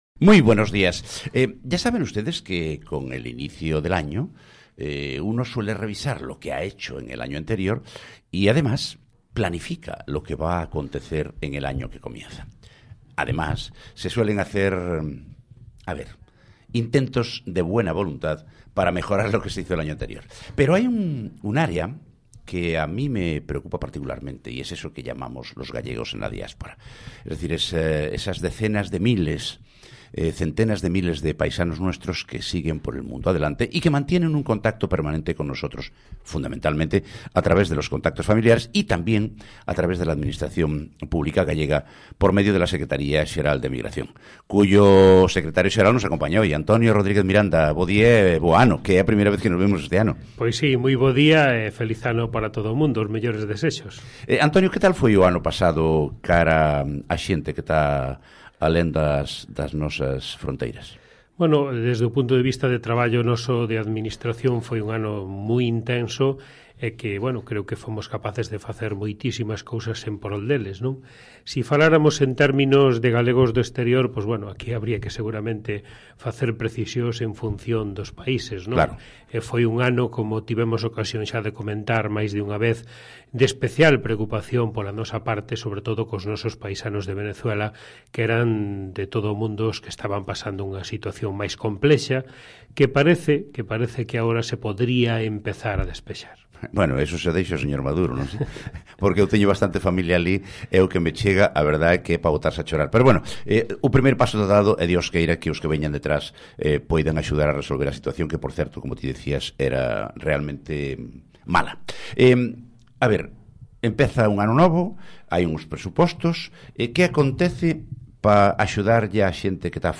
Audio coa entrevista ao secretario xeral da Emigración